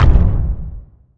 footstep1.wav